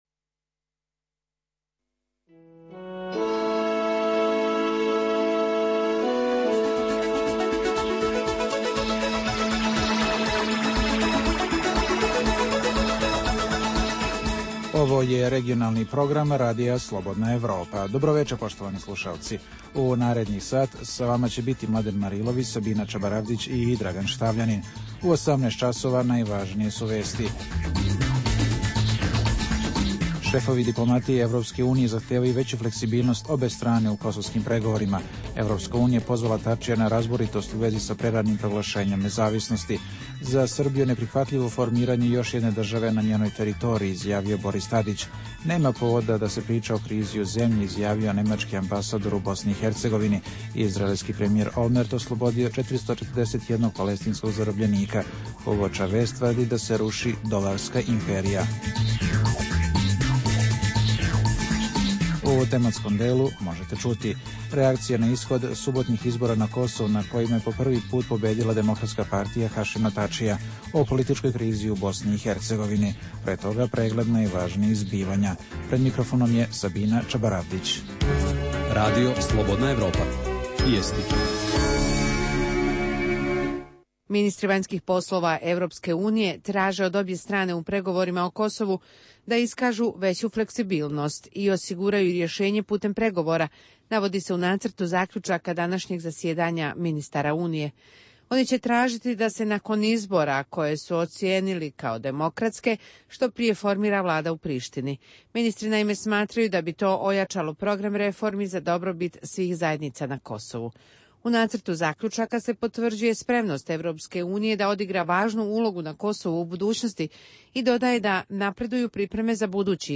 Prvih pola sata emisije sadrži regionalne i vijesti iz svijeta, te najaktuelnije i najzanimljivije teme o dešavanjima u zemljama regiona i teme iz svijeta. Preostalih pola sata emisije, nazvanih “Dokumenti dana” sadrži analitičke teme, intervjue i priče iz života.